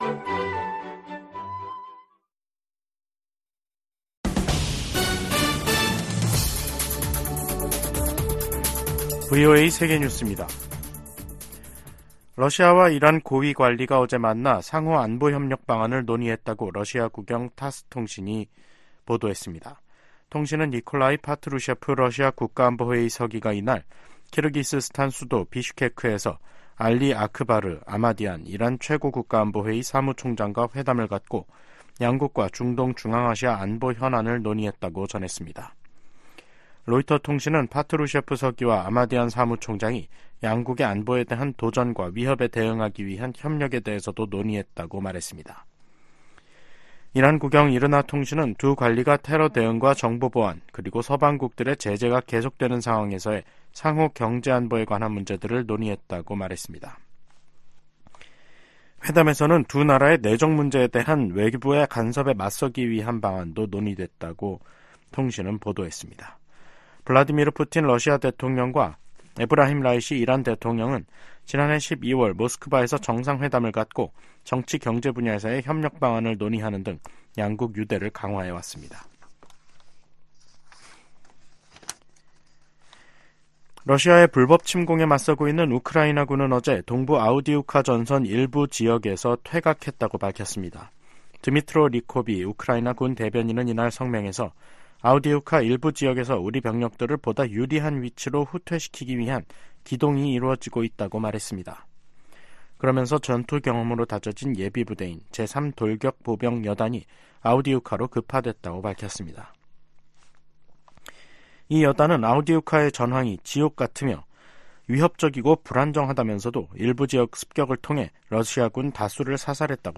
VOA 한국어 간판 뉴스 프로그램 '뉴스 투데이', 2024년 2월 16일 2부 방송입니다. 미국 고위 당국자들이 북한-러시아 관계에 우려를 나타내며 국제 협력의 중요성을 강조했습니다. 김여정 북한 노동당 부부장은 일본 총리가 평양을 방문하는 날이 올 수도 있을 것이라고 말했습니다. 미 재무부는 대북단체 등이 거래할 수 있는 북한 정권의 범위를 명확히 하고 다른 행정부에서 허가 받은 사안에 대해선 중복 신청을 안 해도 되도록 제재규정을 보완했습니다.